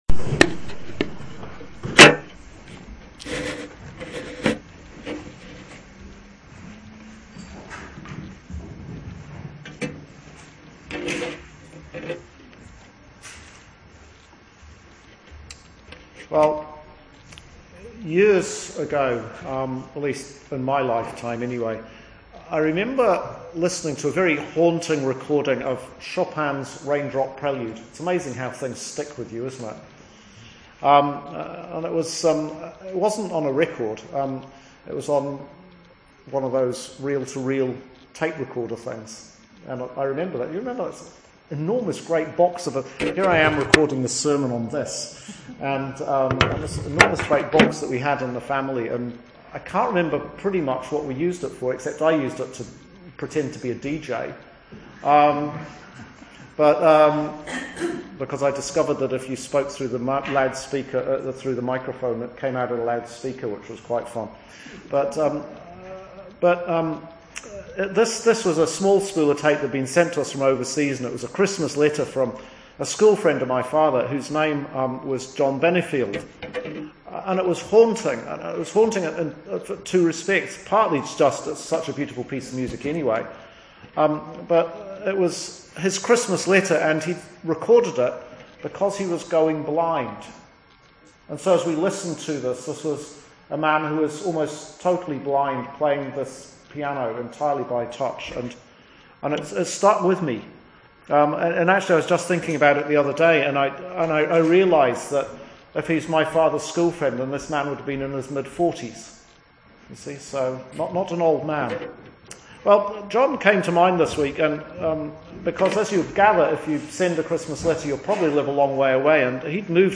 Sermon for Lent 2 – Year B Sunday March 1st 2015